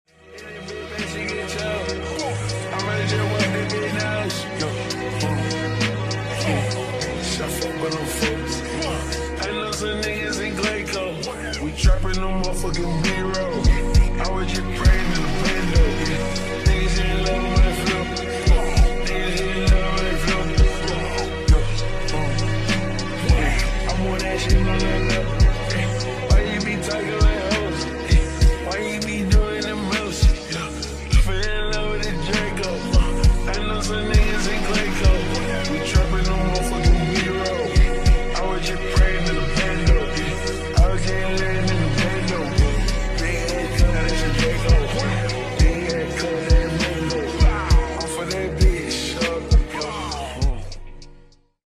747 at Melbourne airport 🔥 sound effects free download